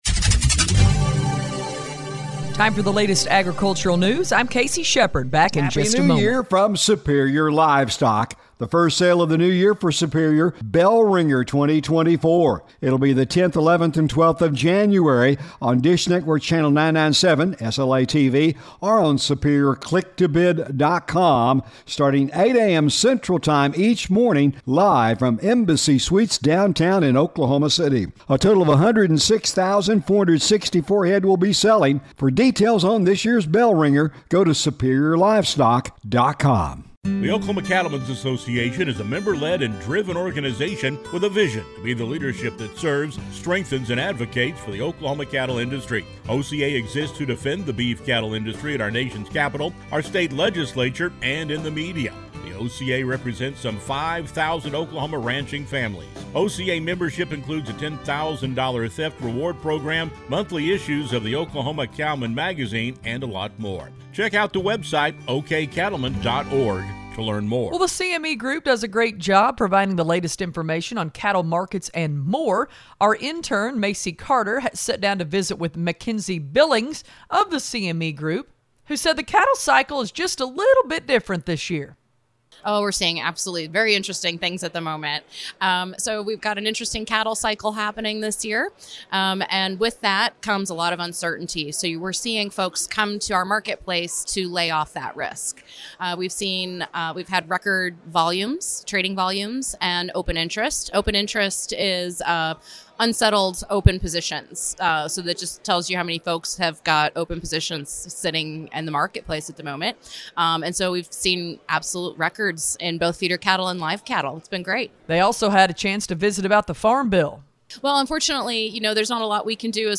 Farm news